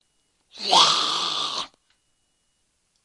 食尸鬼的声音
标签： 嘈杂 食尸鬼 恶魔 可怕
声道立体声